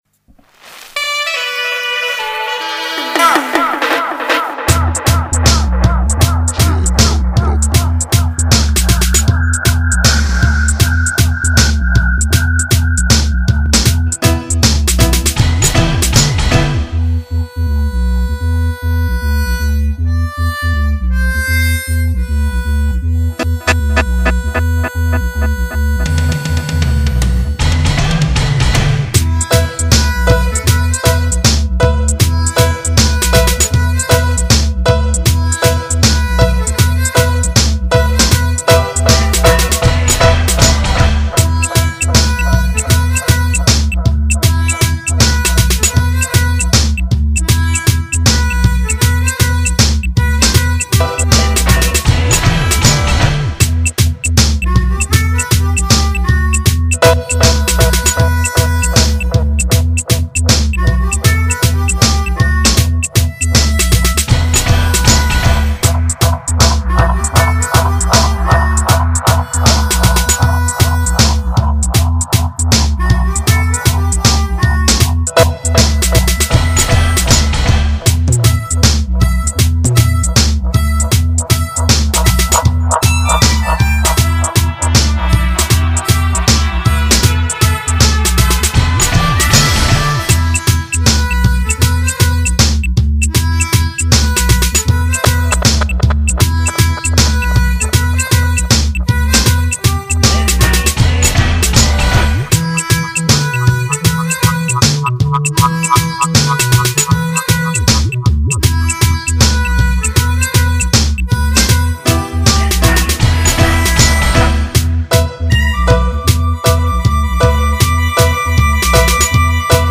special dubplate always buss out a floor all ova....